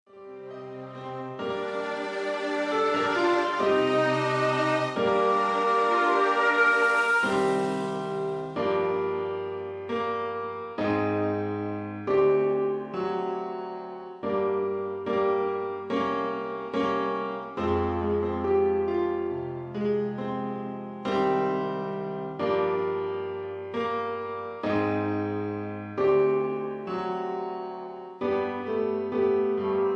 backing tracks
pop music, easy listening